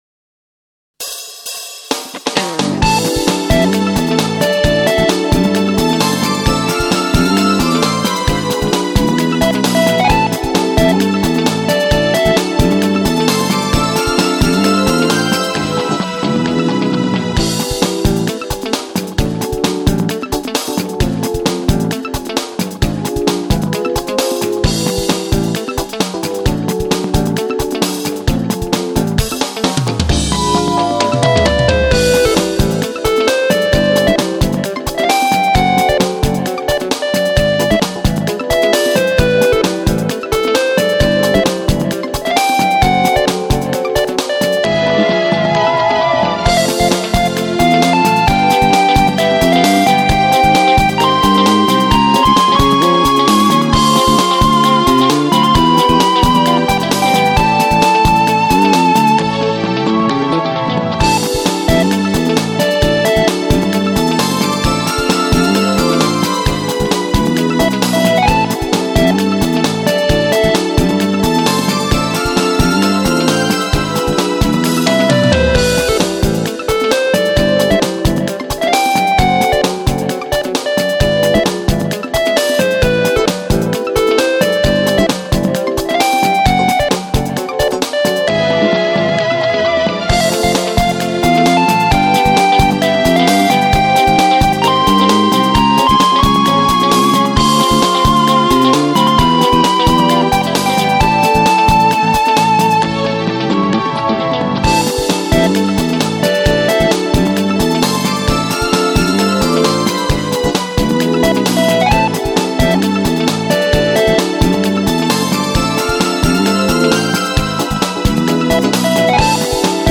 ファイルは「YAMAHA MU1000EX」「Roland SC-8850、INTEGRA-7」で制作したものを